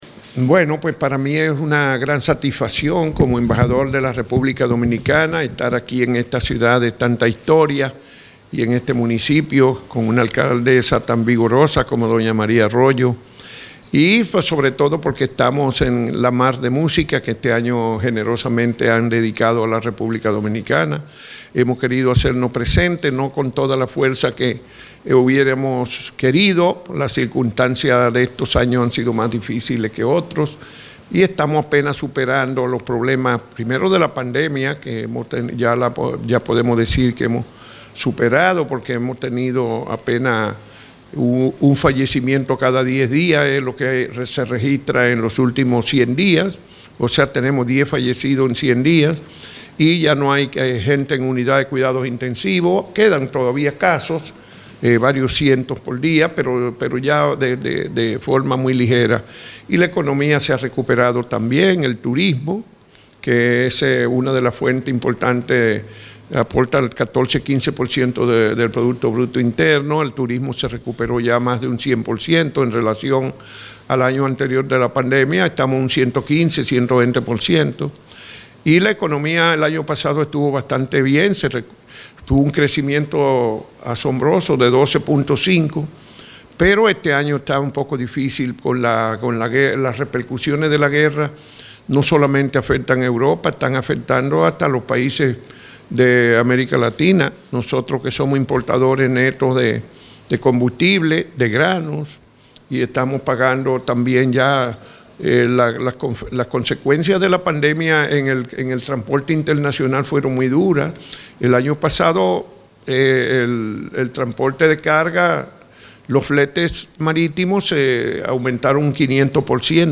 Audio: Declaraciones de Noelia Arroyo y el embajador de Rep�blica Dominicana (MP3 - 9,42 MB)